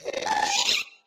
Sound / Minecraft / mob / ghast / charge.ogg
charge.ogg